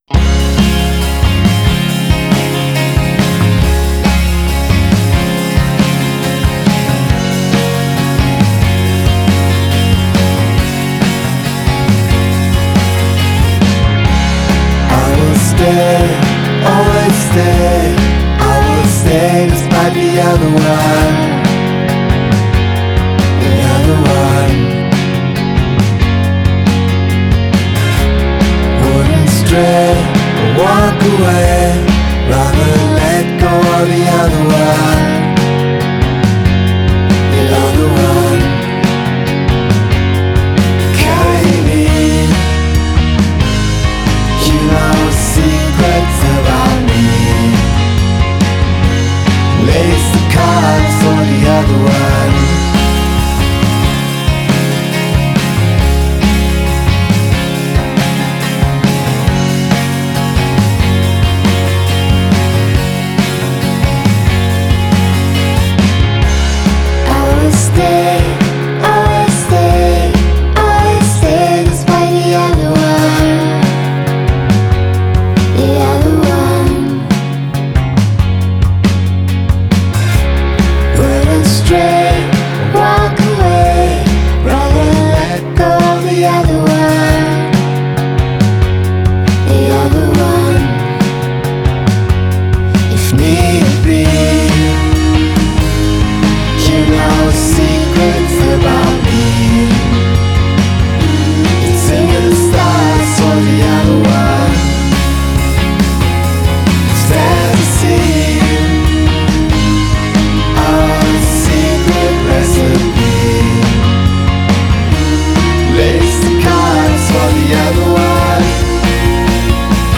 That all without denying their roots in 90s indie pop.